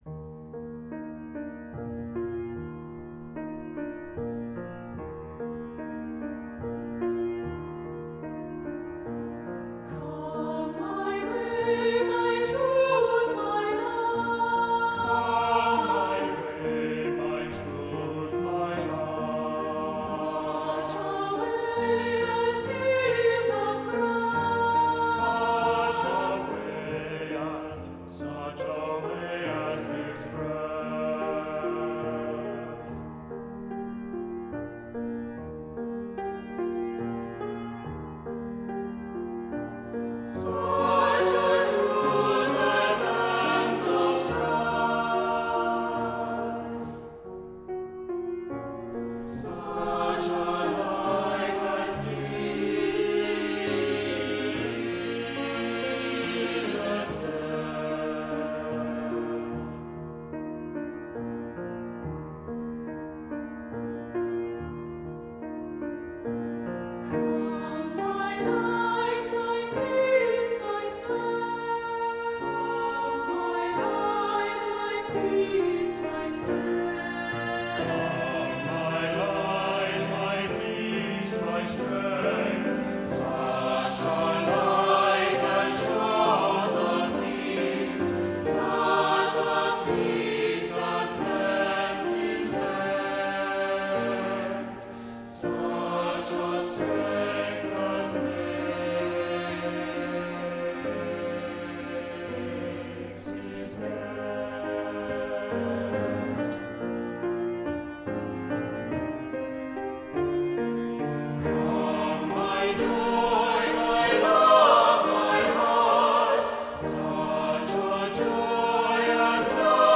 Voicing: "SAB"